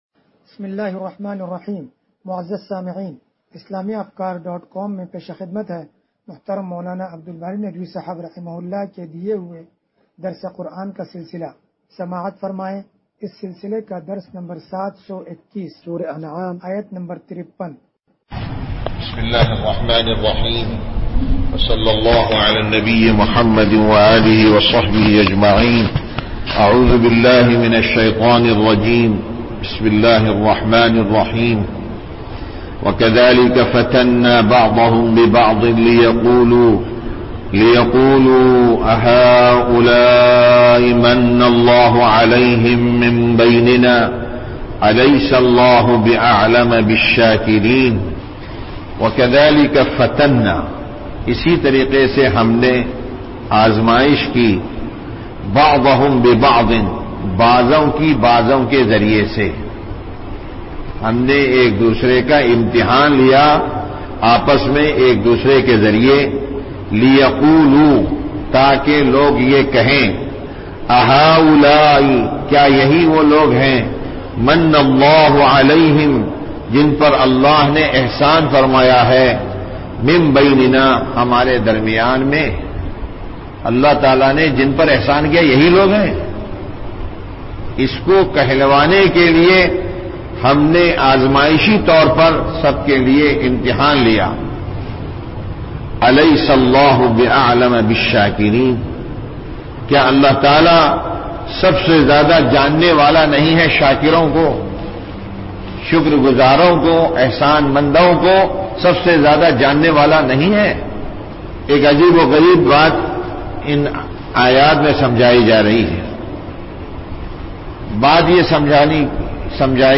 درس قرآن نمبر 0721